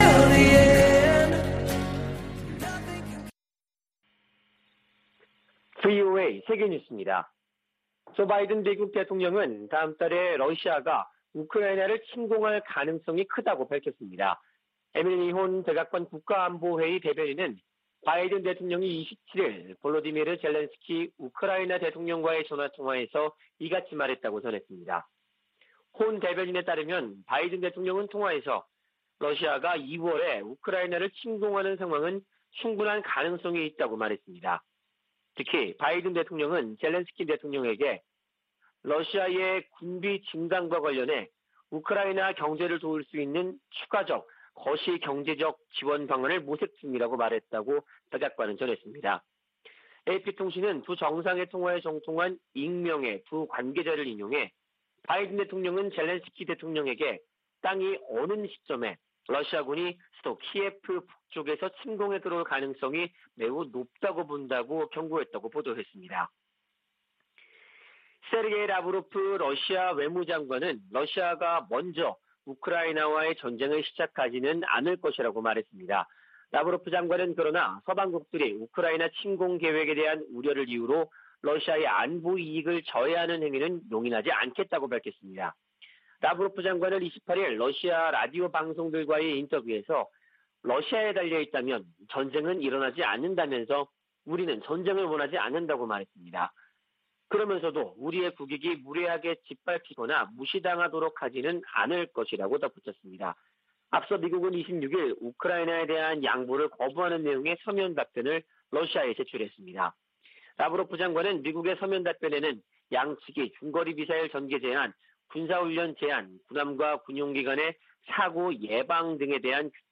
VOA 한국어 아침 뉴스 프로그램 '워싱턴 뉴스 광장' 2021년 1월 29일 방송입니다. 북한이 27일의 지대지 전술유도탄 시험발사와 지난 25일의 장거리 순항미사일 시험발사에 각각 성공했다고 28일 공개했습니다. 미 국무부는 외교 우선 대북 접근법을 확인하면서도, 도발하면 대가를 치르게 하겠다는 의지를 분명히 했습니다. 미 국방부는 북한의 불안정한 행동을 주목하고 있다며 잇따른 미사일 발사를 ‘공격’으로 규정했습니다.